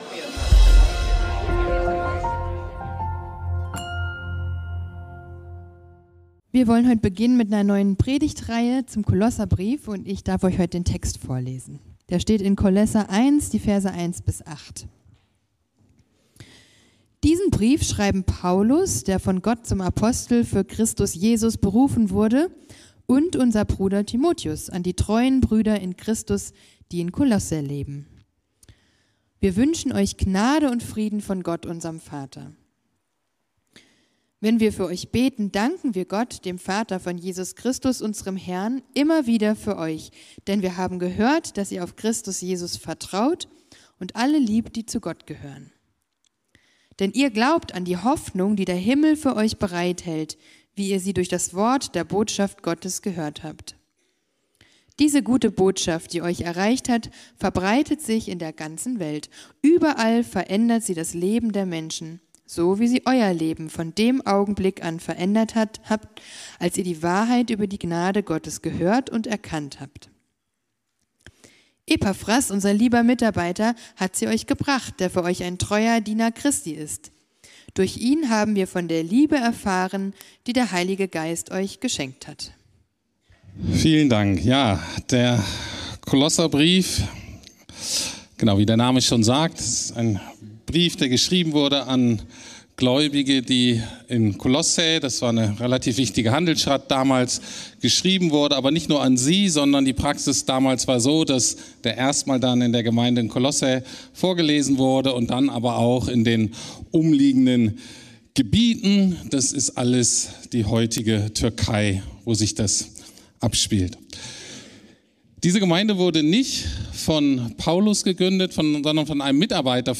Kolosserbrief - Glaube, Liebe, Hoffnung ~ Predigten der LUKAS GEMEINDE Podcast